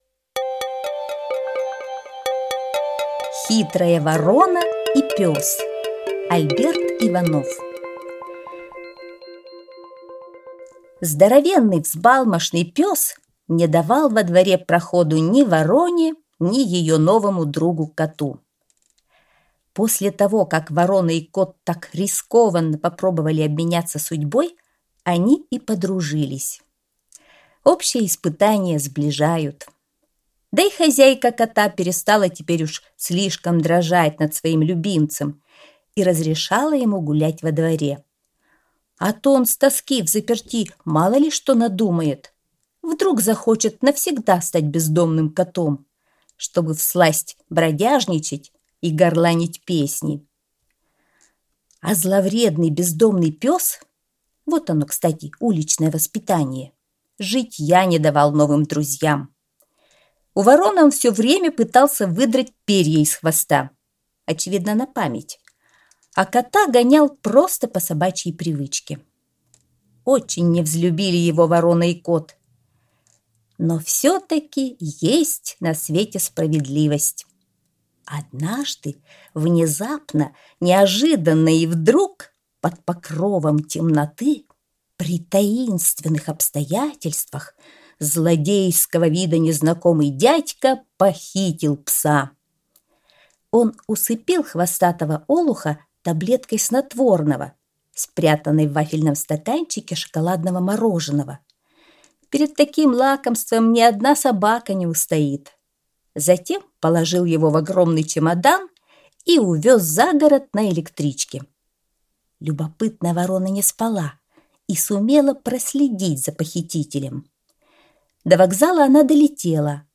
Аудиосказка «Хитрая ворона и пёс»